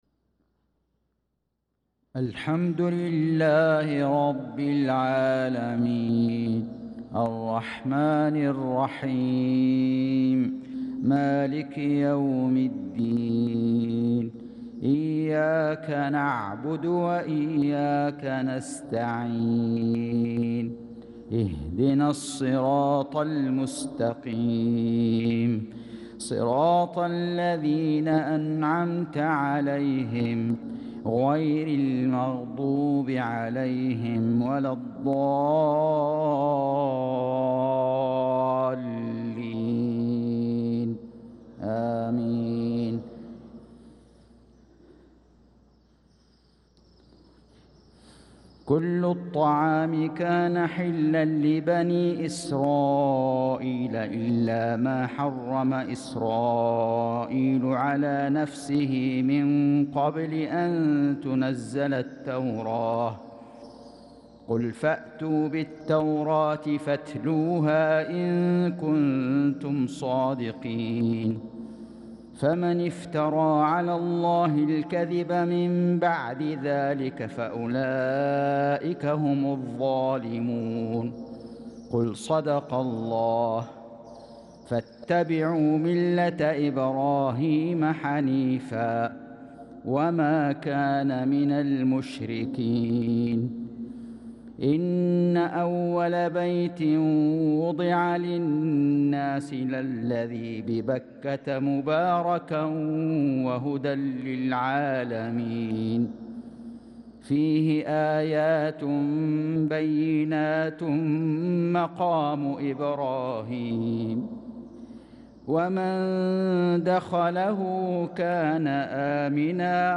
صلاة المغرب للقارئ فيصل غزاوي 22 ذو القعدة 1445 هـ
تِلَاوَات الْحَرَمَيْن .